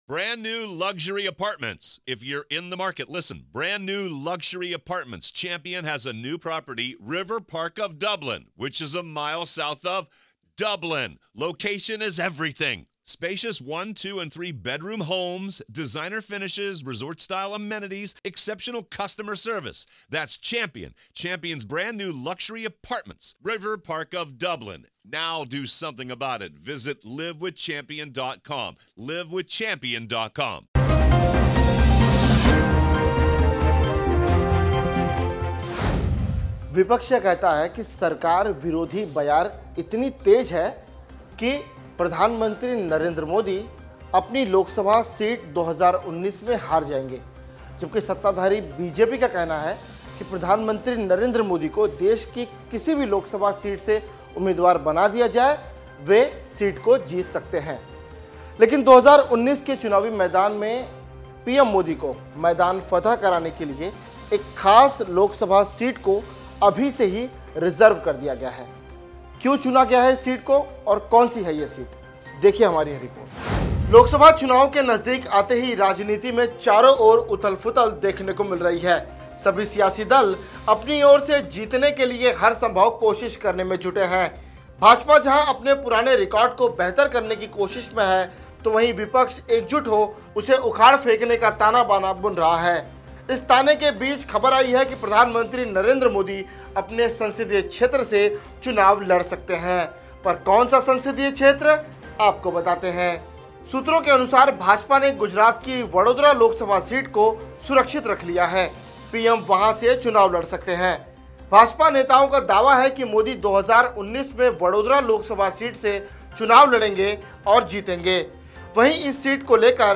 न्यूज़ रिपोर्ट - News Report Hindi / वाराणसी नहीं अब नरेंद्र मोदी इस सीट से लड़ सकते हैं लोकसभा चुनाव !